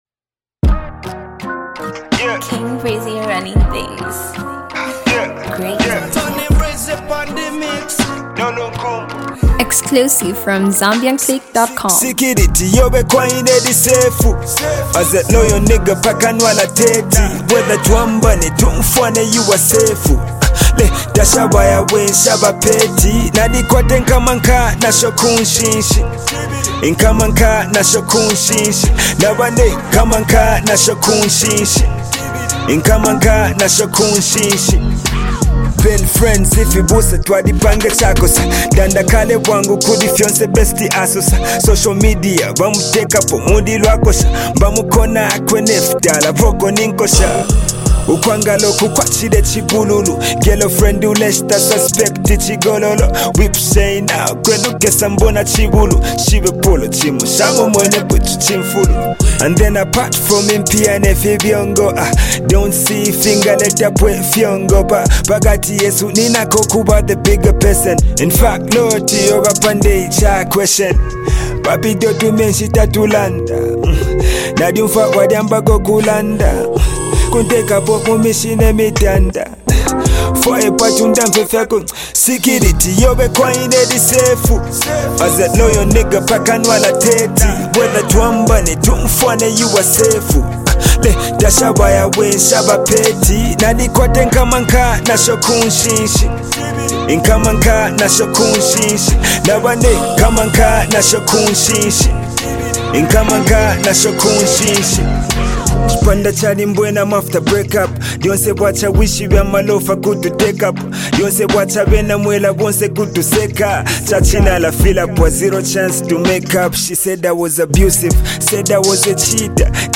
Zambian hip-hop artiste